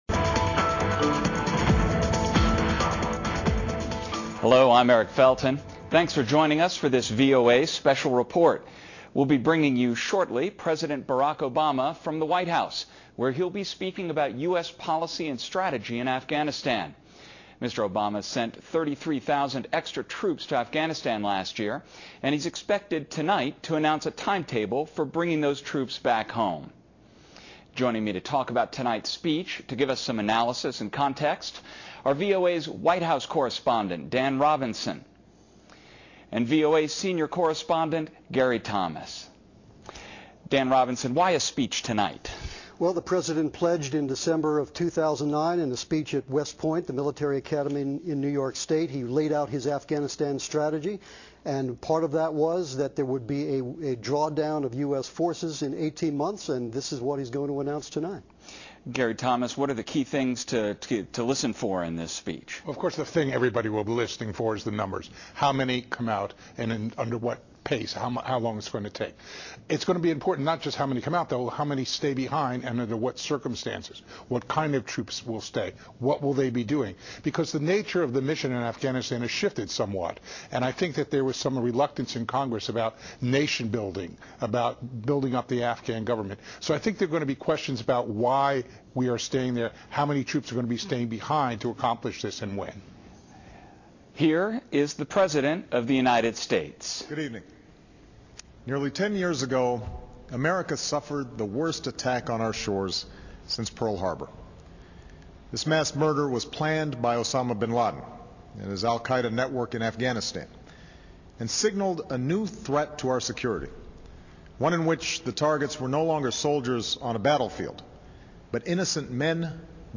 President_Obama_Afghanistan_Speech.mp3